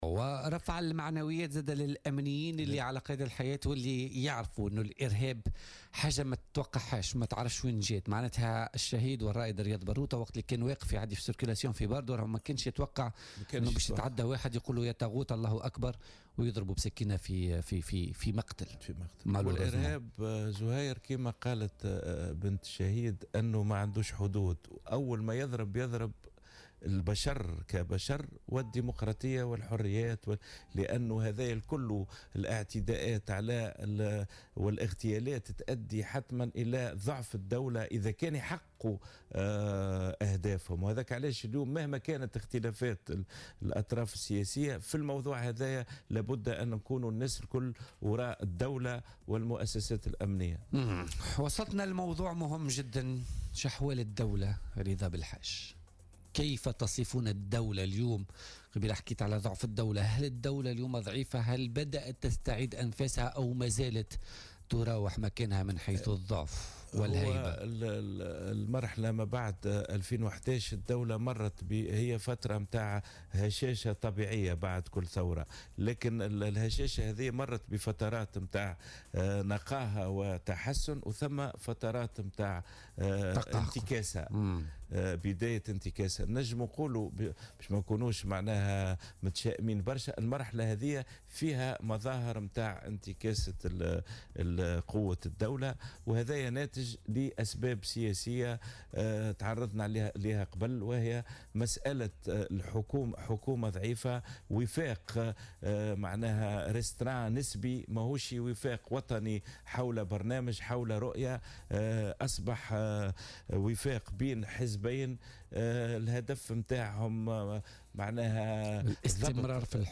قال رضا بلحاج منسق حزب تونس أولا والقيادي السابق في حركة نداء تونس ضيف بولتيكا اليوم الجمعة 03 نوفمبر 2017 إن تونس تشهد حاليا مرحلة انتكاسة وهشاشة على مستوى قوة الدولة على حد قوله.